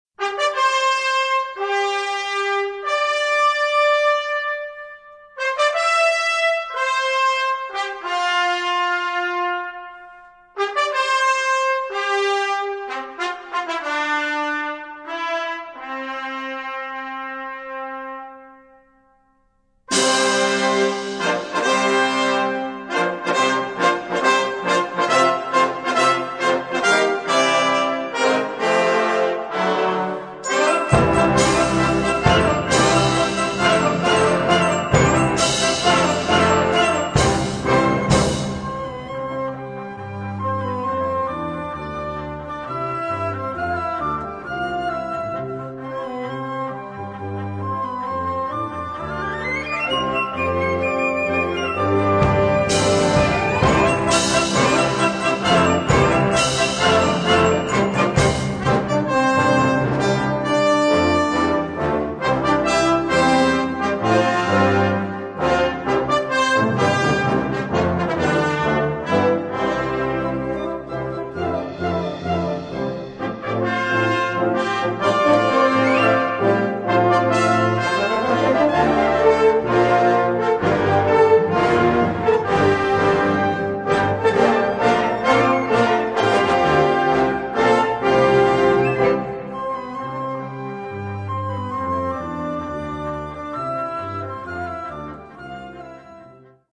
Gattung: Konzertstück
Besetzung: Blasorchester
melodisch, harmonisch und rhythmisch komplexer